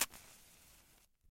声音效果 " 匹配照明2
描述：普通火柴被点燃。
声道立体声